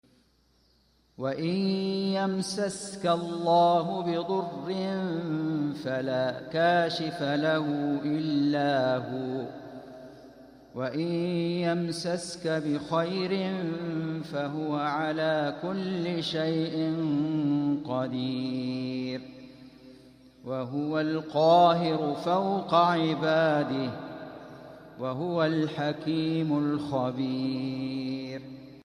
صوته عادي وقراءة عاديه الله يطول بعمره
ادام الله هذا الصوت الشجيِّصادحًا بآيات الله في بيته الحرام، أعوامًا عديدة وأزمنةً مديدة.